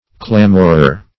Clamorer \Clam"or*er\, n. One who clamors.